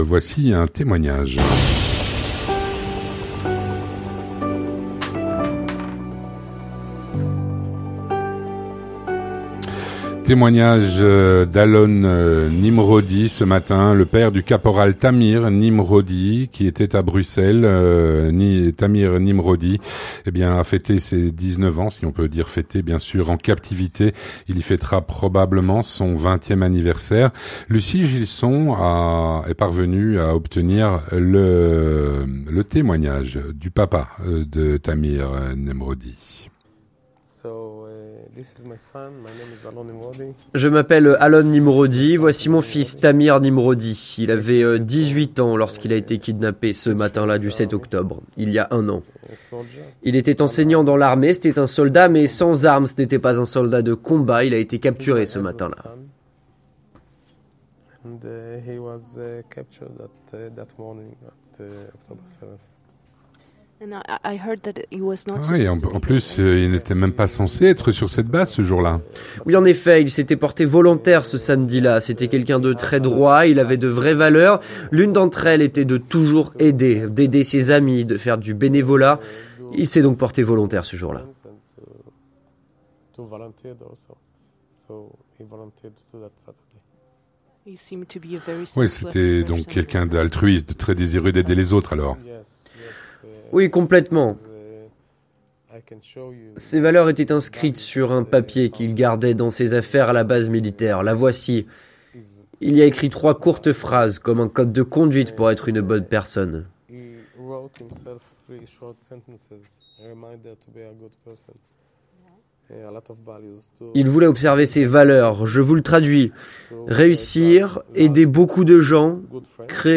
Témoignage
Il témoigne à notre micro.